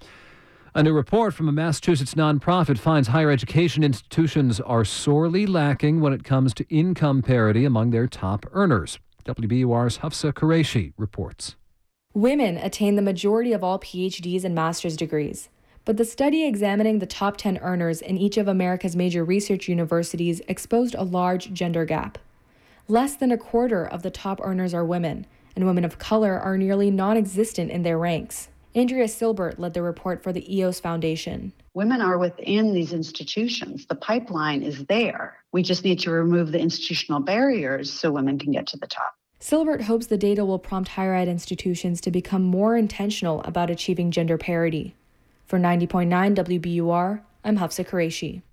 WBUR Interview – Women's Power Gap